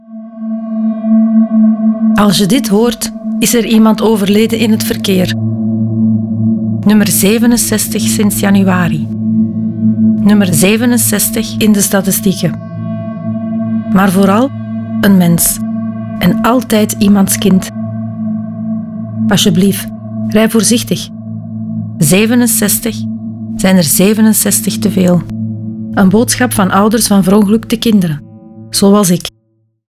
Ouders van Verongelukte Kinderen maken een radiospot voor elk dodelijk verkeersslachtoffer.
De spots werden geproduced door Raygun met de medewerking van échte ouders van verongelukte kinderen, geen acteurs.